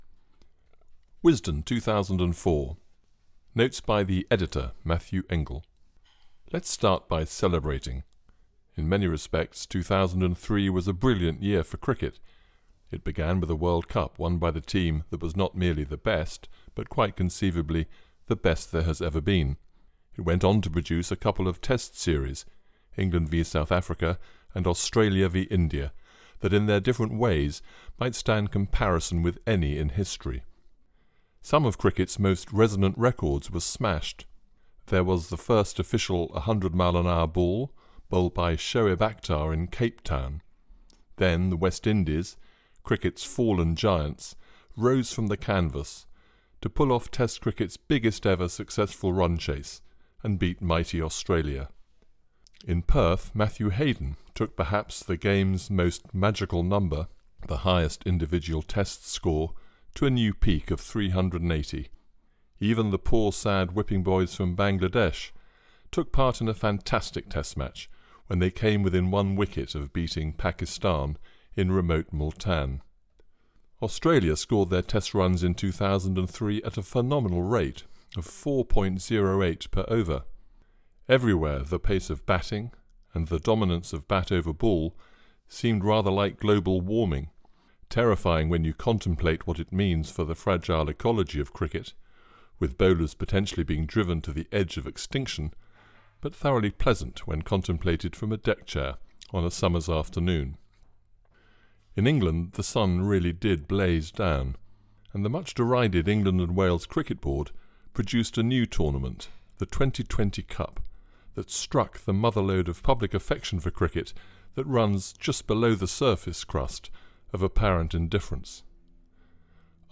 Captivate Your Audience with a Resonant British voice
SPORTS PUBLICATIONS